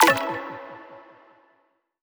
button-play-select.wav